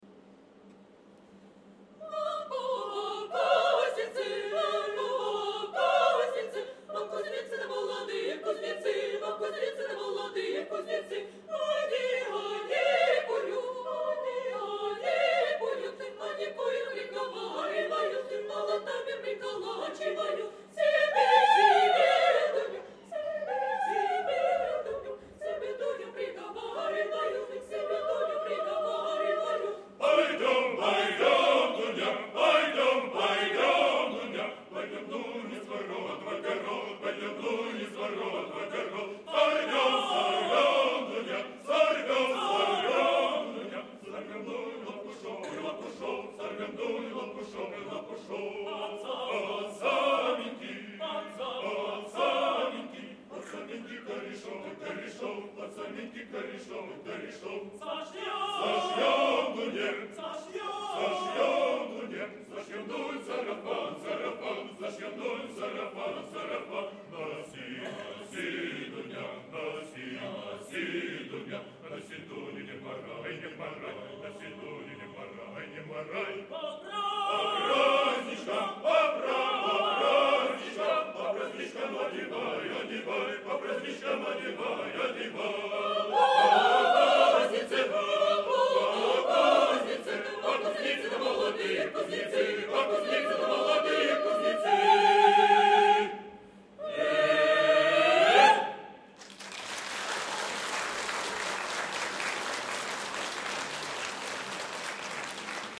Был сегодня на концерте хоровой музыки.